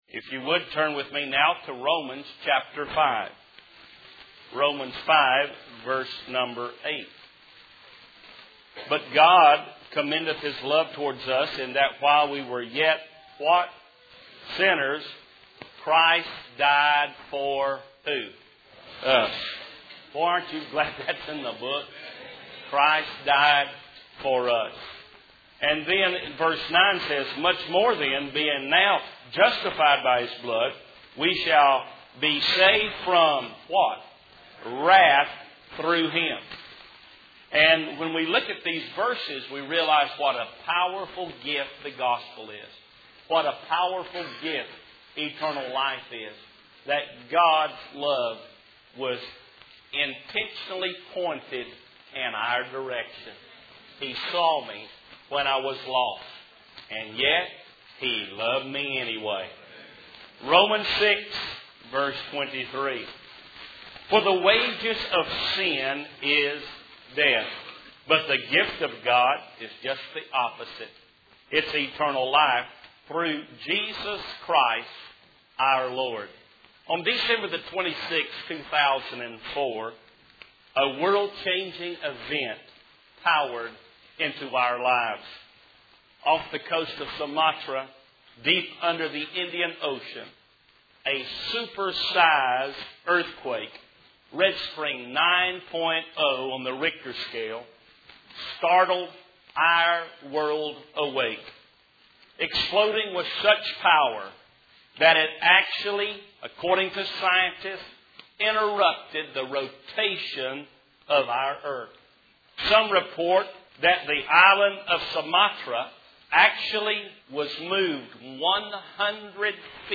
In this sermon, the preacher begins by discussing the devastating 2004 earthquake off the coast of Sumatra.